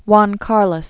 (wän kärləs, -lōs, hwän) Born 1938.